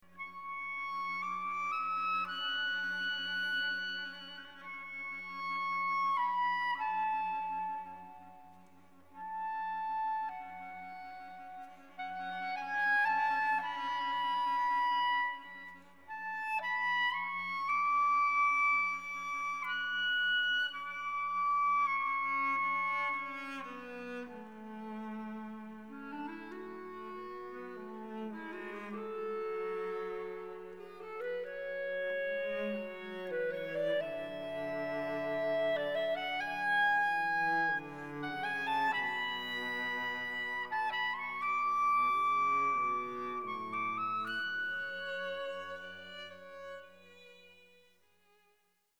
Cantate (choeur, clarinette et cordes)